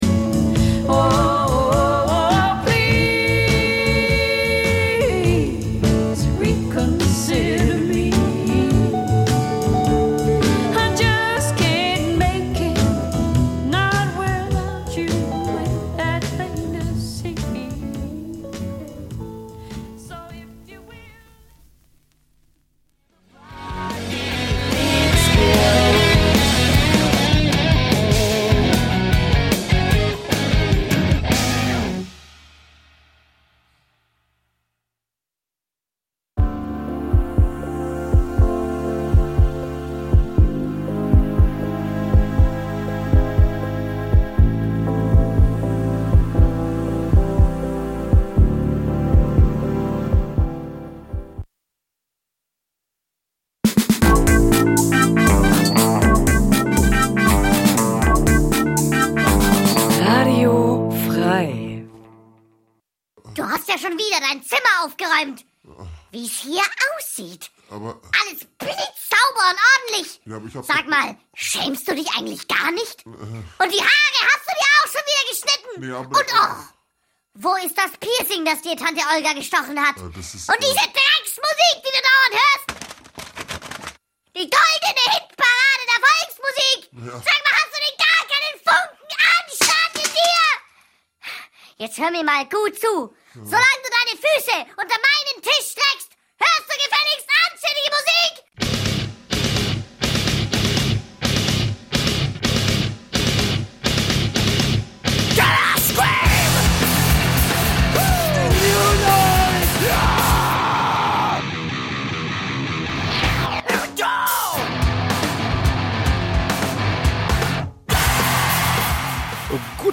...25 Jahre of total Krach... die beste Mischung aus genialen Neuvorstellungen und unerl�sslichen Konzerttips... aus dem old school, Metal-, Rock 'n' Roll-, Grind- und Hardcore- und sonstigen "gute Musik"- Bereichen...